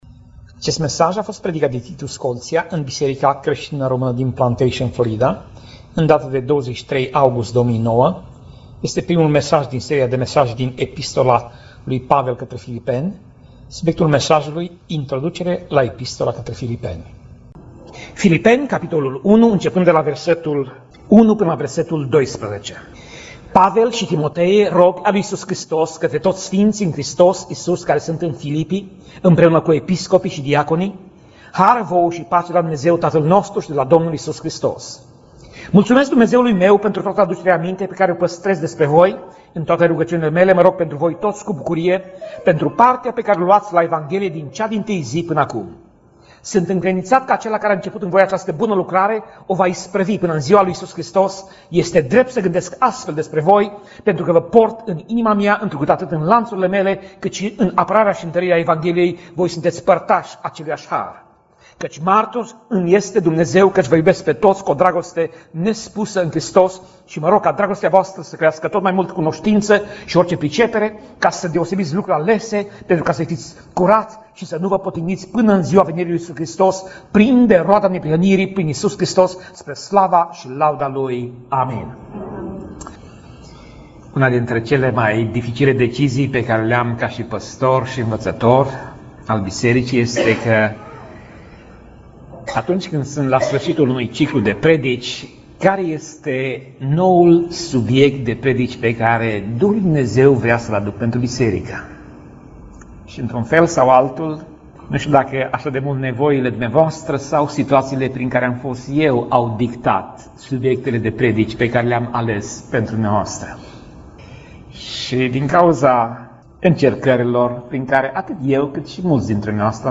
Pasaj Biblie: Filipeni 1:1 - Filipeni 1:11 Tip Mesaj: Predica